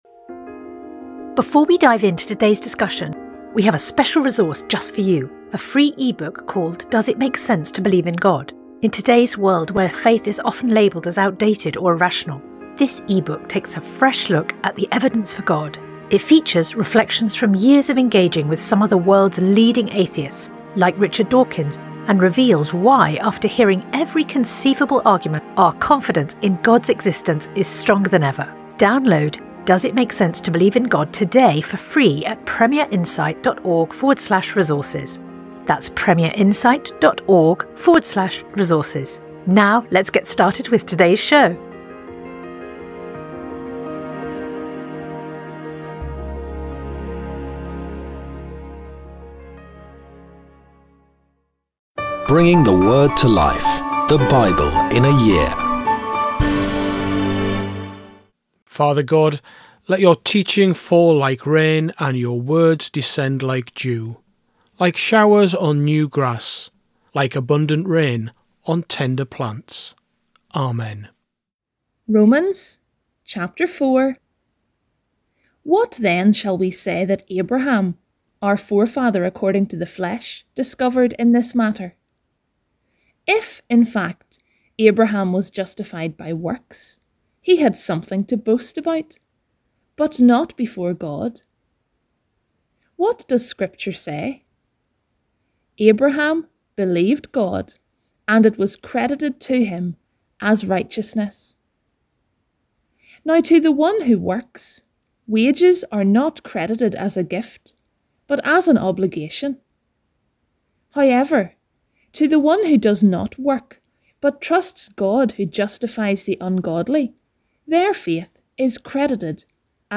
Today’s readings comes from Job 34-35; Romans 4 Sponsored ad Sponsored ad